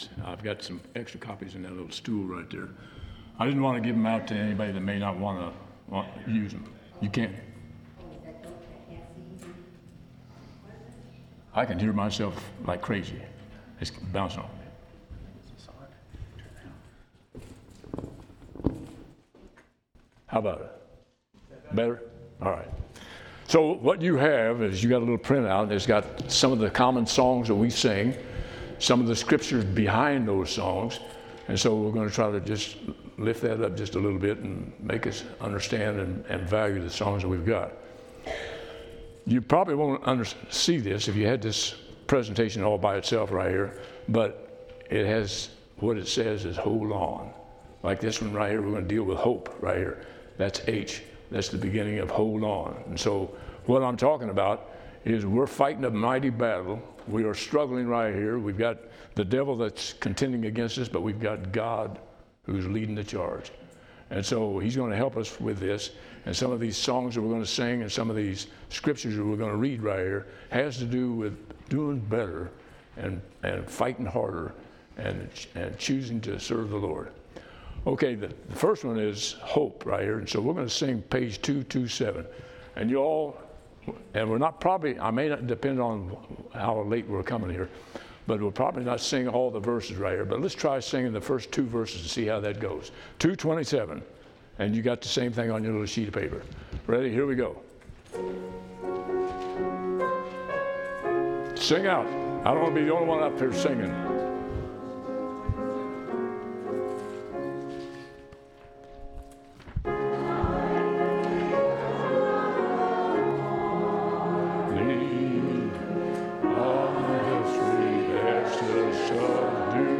Location: Temple Lot Congregation